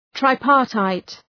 Προφορά
{traı’pɑ:rtaıt}